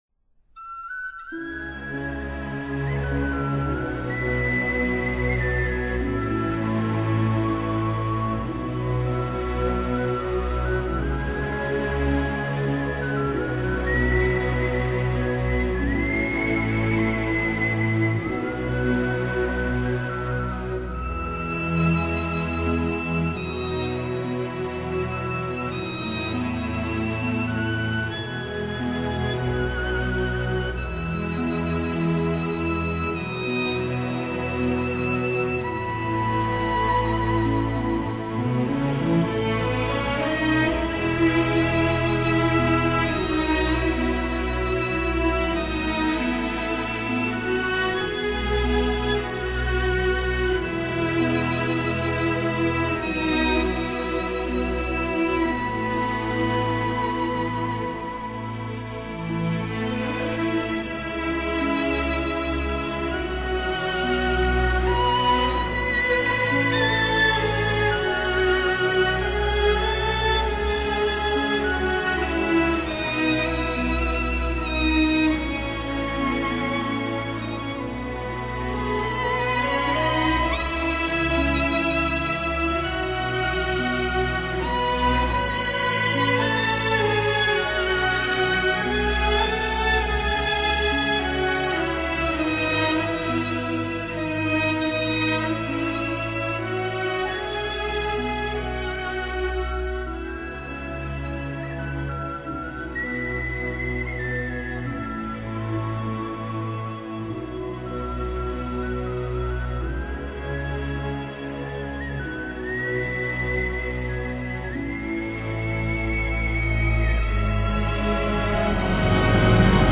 他和自己的小型管弦樂隊默契配合，將古典音樂與大衆音樂進行了有機的融合，給人們的休閒生活提供了輕鬆愉悅的藝術享受。